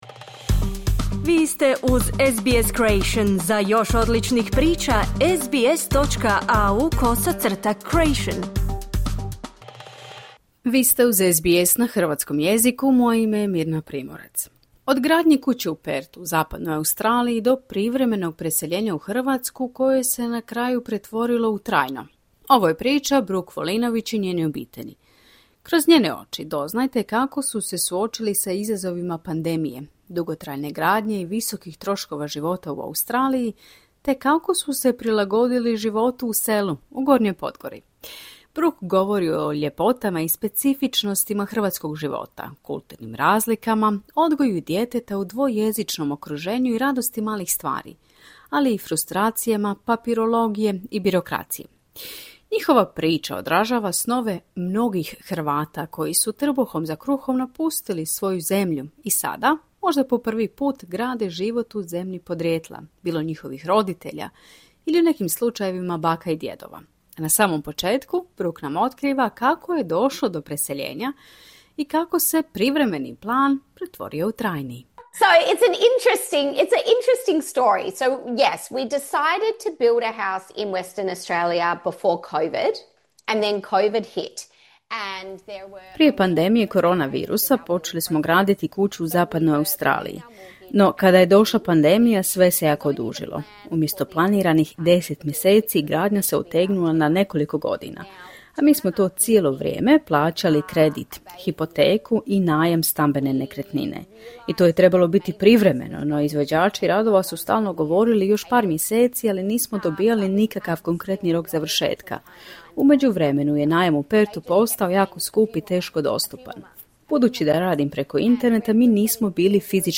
U razgovoru za SBS Croatian otkriva kako su se odlučili preseliti iz Australije u Hrvatsku, govori o izazovima s hrvatskom birokracijom, prilagodbi na život u zemlji podrijetla svojih roditelja, učenju hrvatskog jezika te o svemu onome što čini život u malom selu, Gornjoj Podgori, posebnim, i po njihovoj ocjeni boljim nego u Australiji.